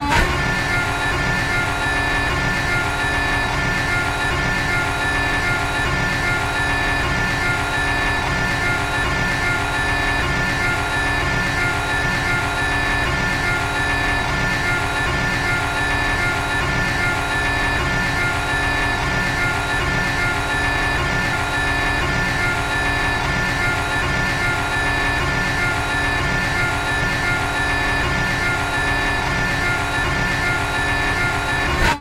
На этой странице собраны звуки турели — от механизма поворота до залповой стрельбы.